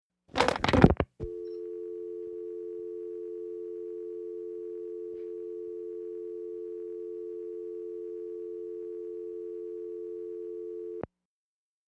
Telephone hang - up dial tone, in - line